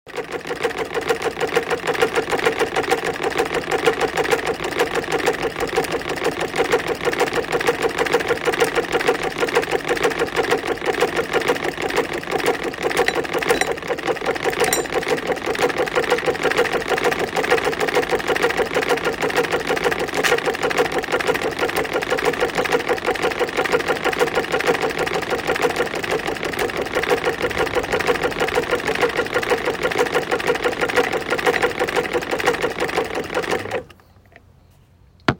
From the attic of a friend's lost parents, obsolete as it is a hand cranked sewing machine and the memories as a child as I tried to fix various bits of clothing and how the thread was fed. This is part of the Obsolete Sounds project , the world’s biggest collection of disappearing sounds and sounds that have become extinct – remixed and reimagined to create a brand new form of listening.